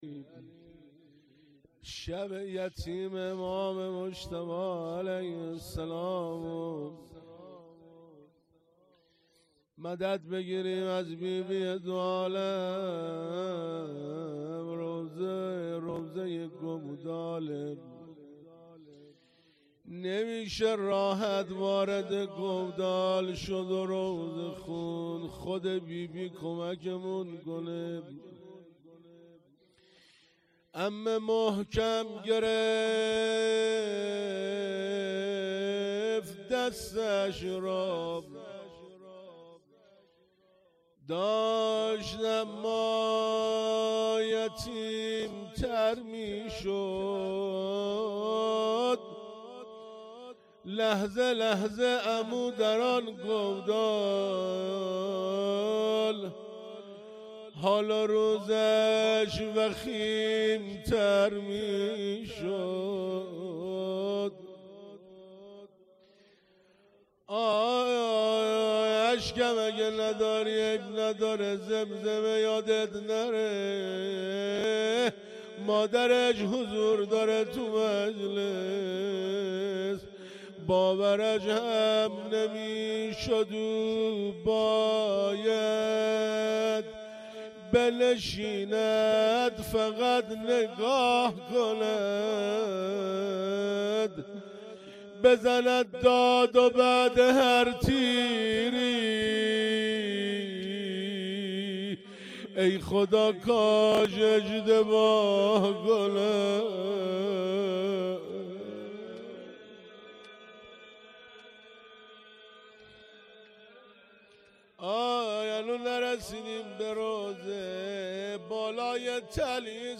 هیئت امام سجاد (علیه السلام) متوسلین به بی بی شهربانو (سلام الله علیها)
شب پنجم محرم 98